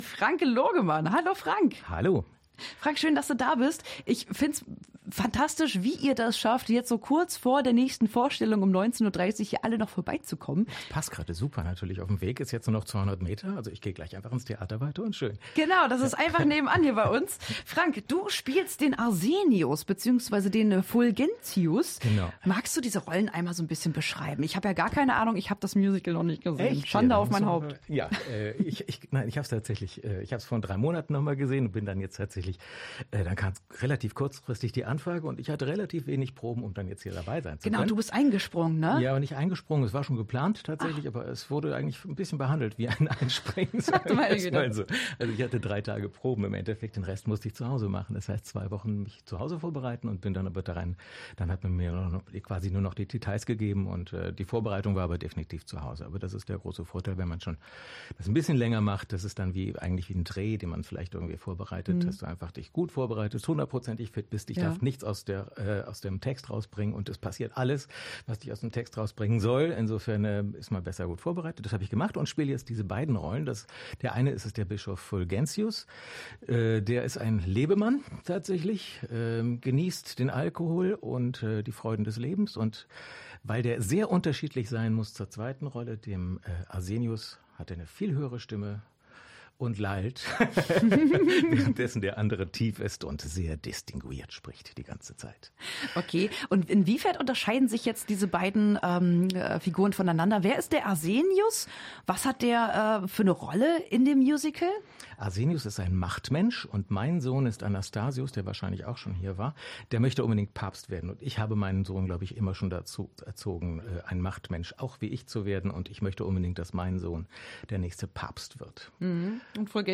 Hameln: Live-Interview